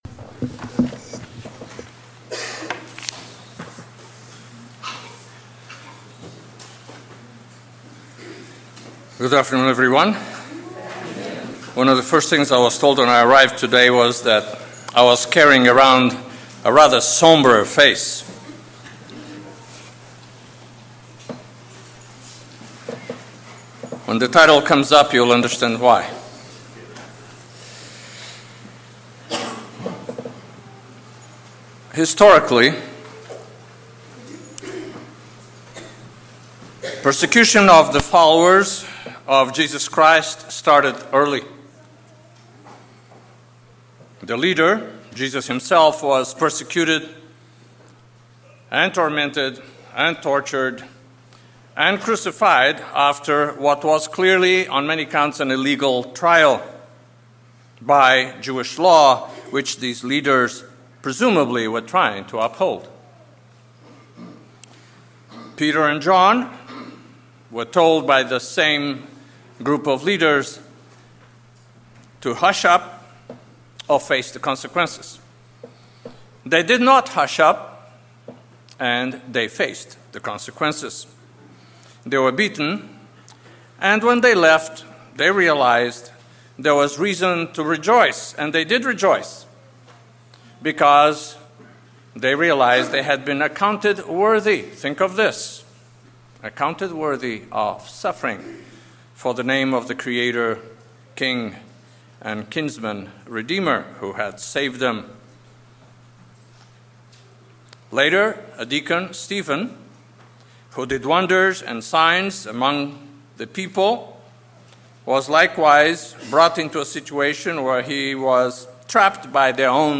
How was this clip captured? Given in Petaluma, CA San Francisco Bay Area, CA